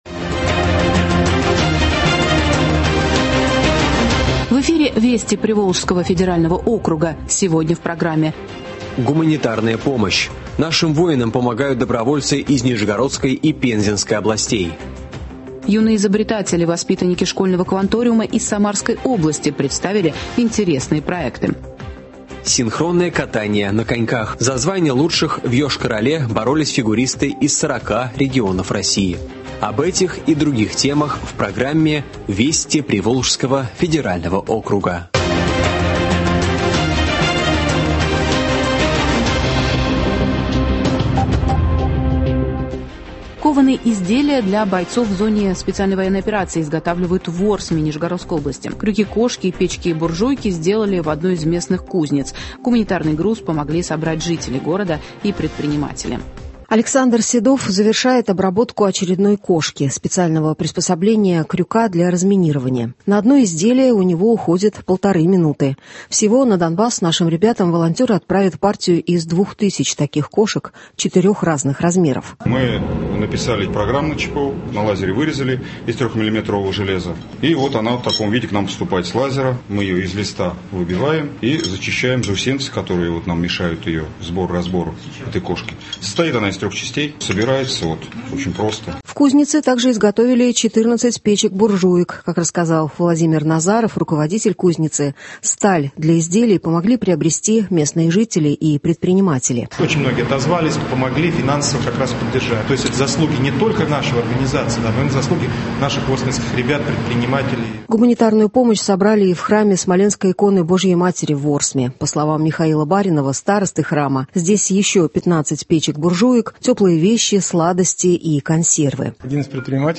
Радио обзор событий недели в регионах ПФО.